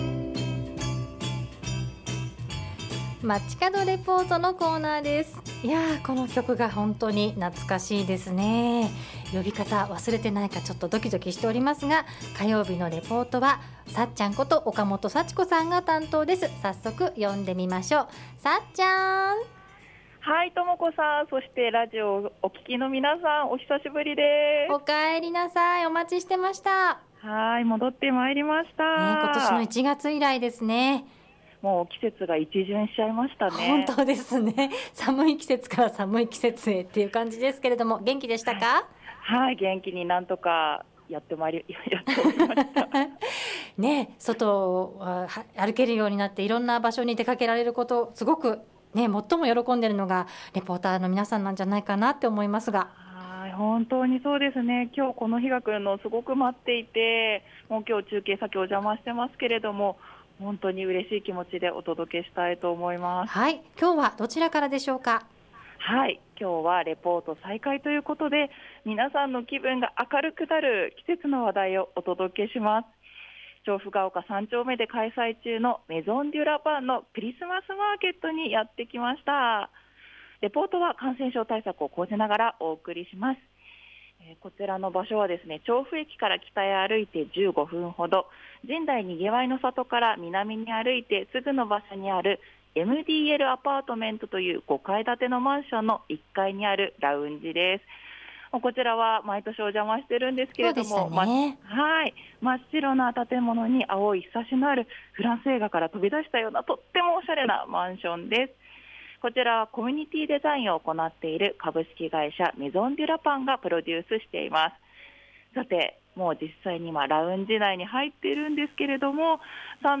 今週から調布の街の魅力を中継でお伝えしていきますね。